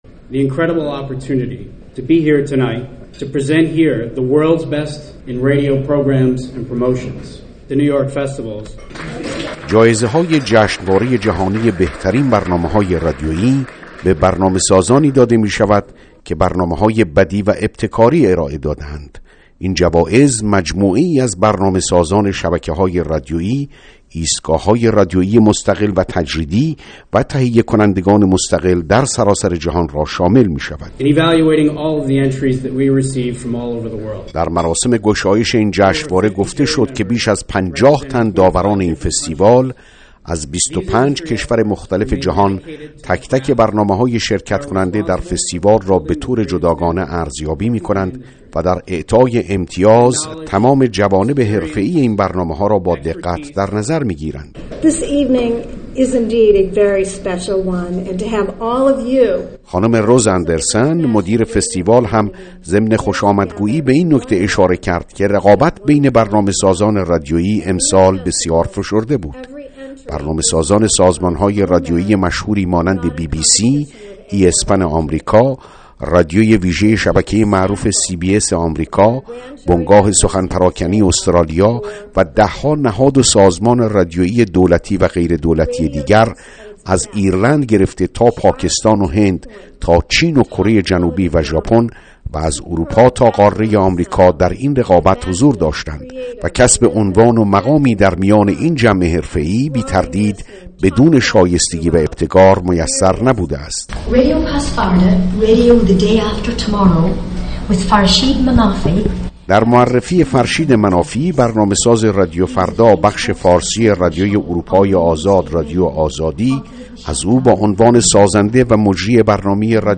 گزارش
از مراسم «نیویورک فستیوالز»